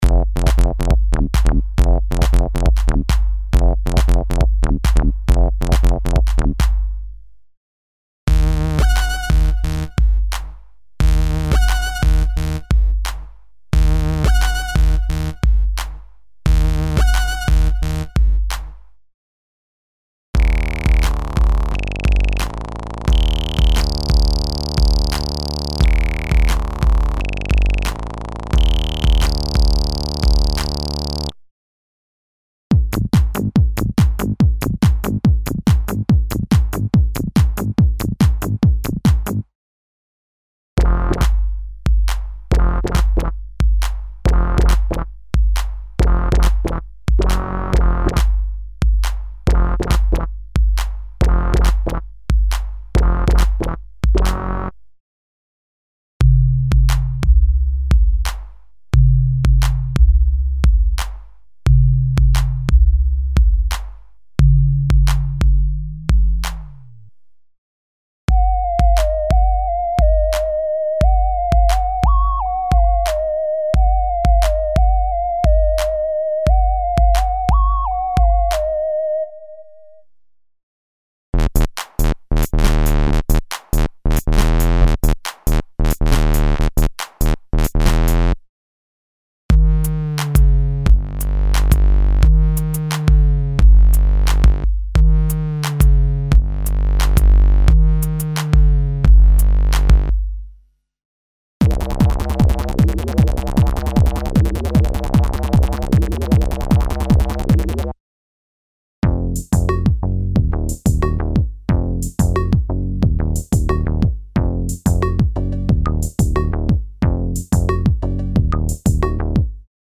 Original collection of analog & digital basses and leads with modern feeling for a wide variety of music styles (Techno, House, Trance, Jungle, Rave, Break Beat, Drum´n´Bass, Euro Dance, Hip-Hop, Trip-Hop, Ambient, EBM, Industrial, etc.).